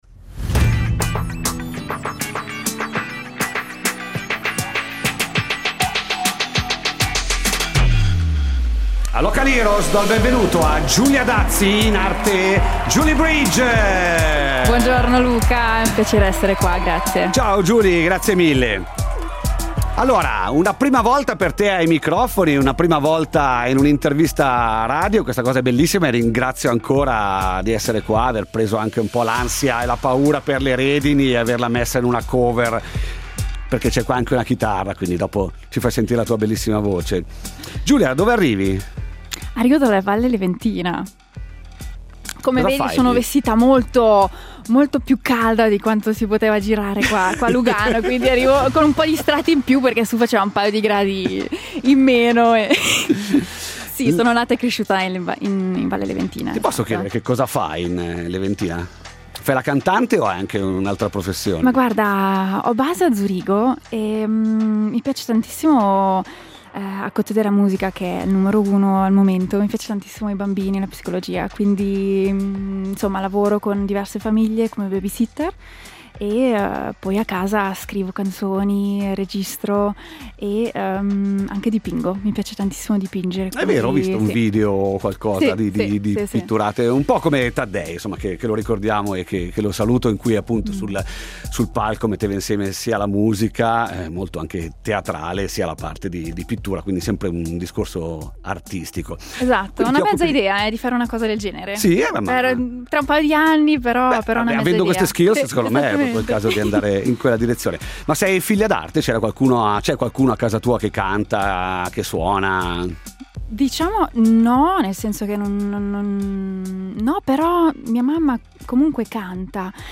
La sentiremo raccontarsi, cantare e suonare Live!